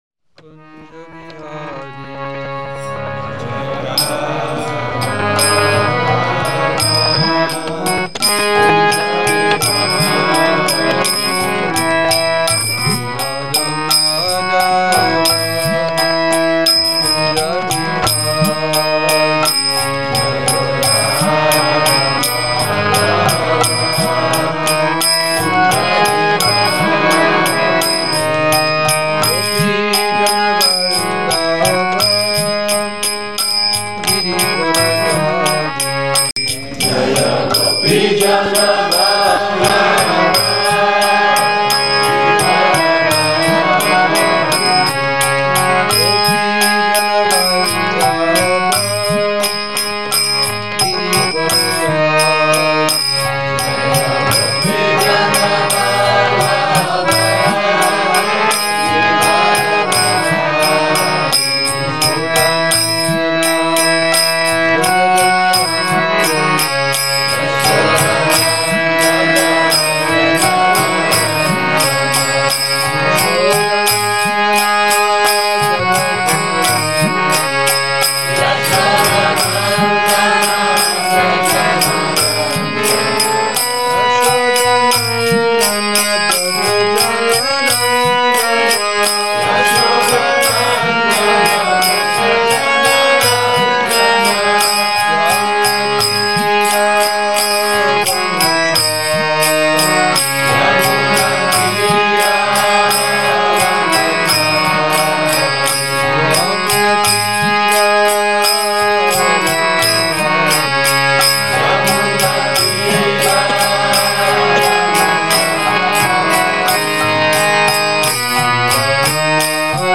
Přednáška SB-2.7.4